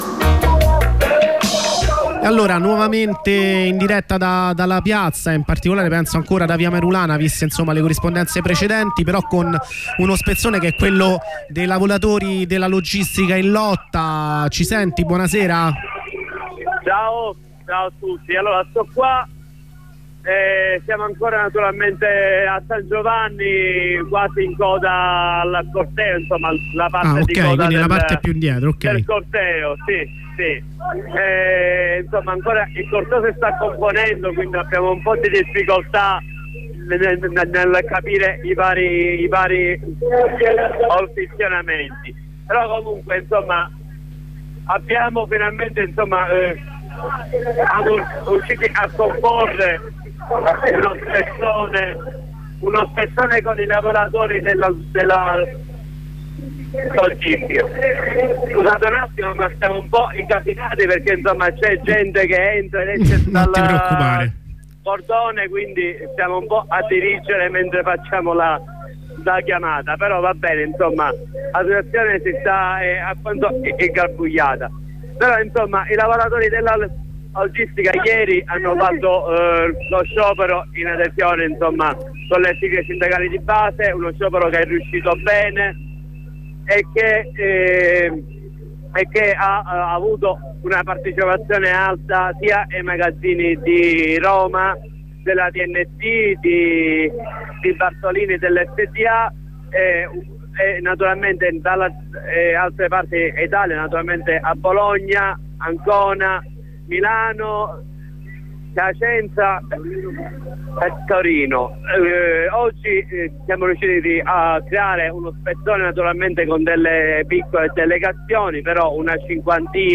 Una corrispondenza dalla coda del corteo con un lavoratore della logistica di Roma, una con un attivista no tav di avigliana e una terza corrispondenza dallo spezzone della lotta per la casa di Roma